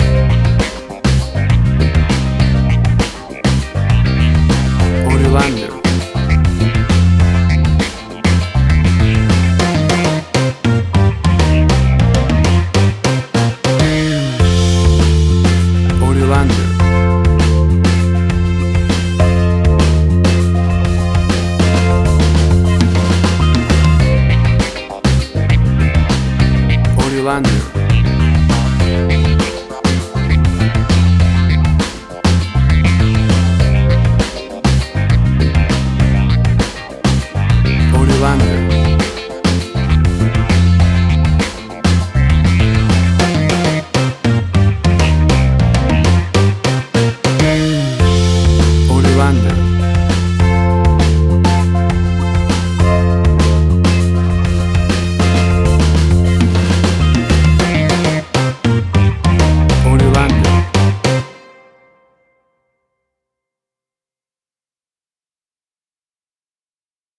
WAV Sample Rate: 16-Bit stereo, 44.1 kHz
Tempo (BPM): 100